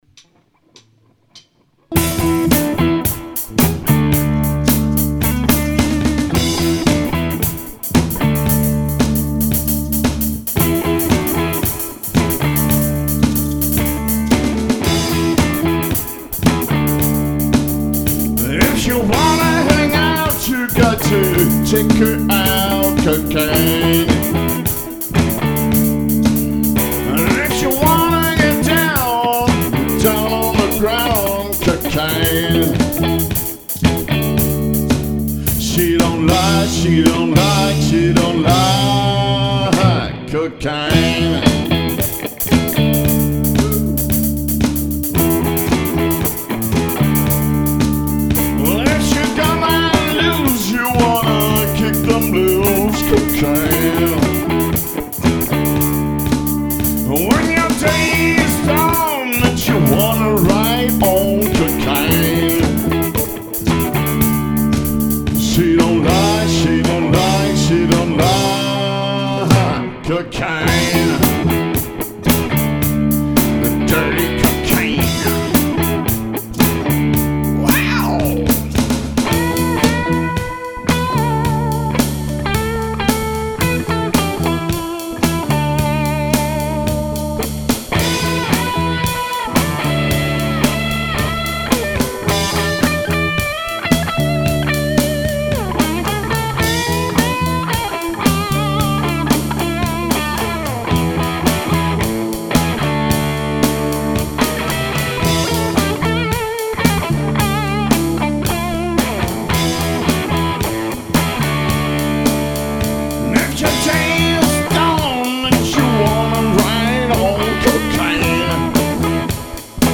Erlebe die kraftvolle Mischung aus Rock und Blues